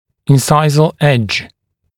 [ɪn’saɪzəl eʤ][ин’сайзэл эдж]режущий край резца